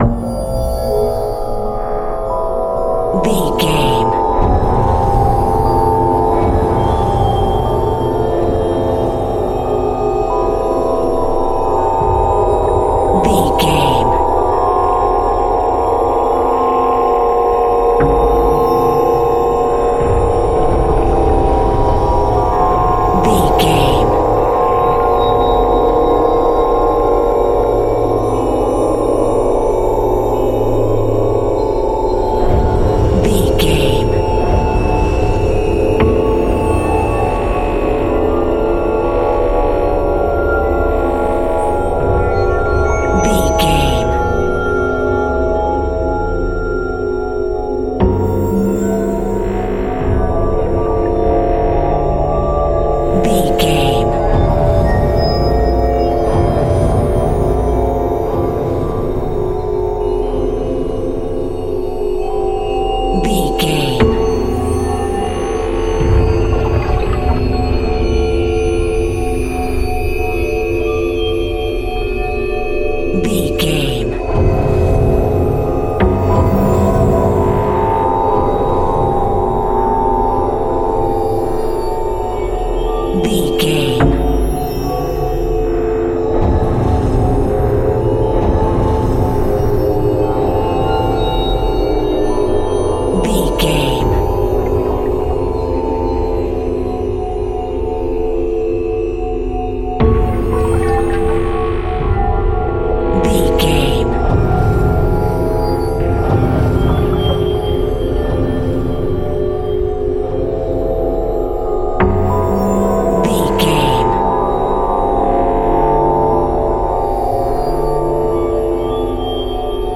Thriller
Atonal
ominous
dark
suspense
haunting
eerie
synthesizers
Synth Pads
atmospheres